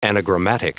Prononciation du mot anagrammatic en anglais (fichier audio)
Prononciation du mot : anagrammatic
anagrammatic.wav